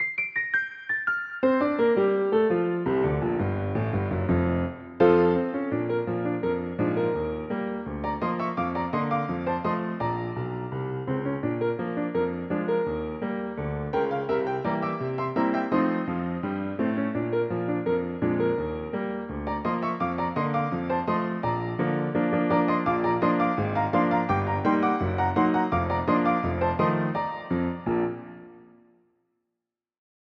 Звучание эпохи немого кинематографа